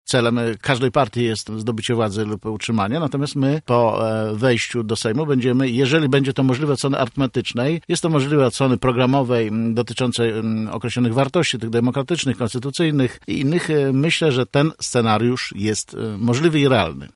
Jacek Czerniak, przewodniczący rady wojewódzkiej Sojuszu Lewicy Demokratycznej i zarazem kandydat numer 1 na liście Lewicy do Sejmu w Porannej Rozmowie Radia Centrum zaprzeczył jakoby jego ugrupowanie było za zalegalizowaniem adopcji dzieci przez małżeństwa homoseksualne.